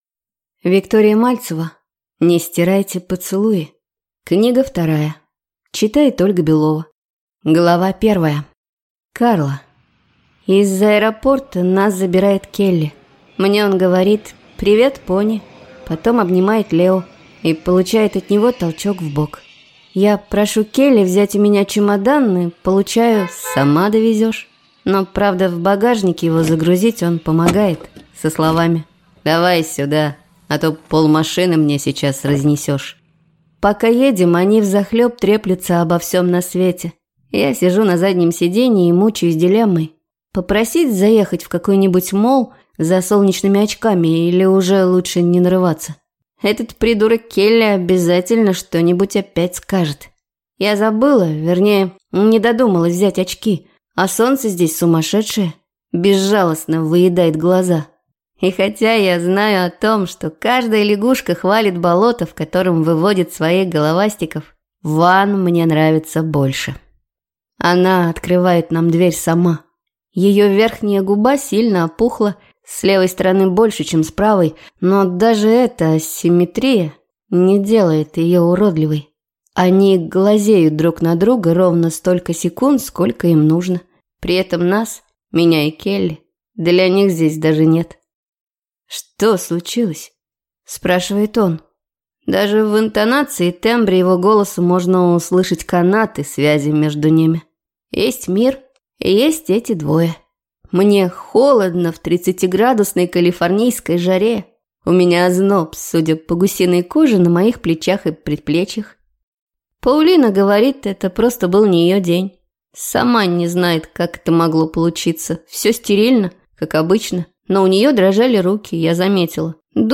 Аудиокнига Не стирайте поцелуи. Книга 2 | Библиотека аудиокниг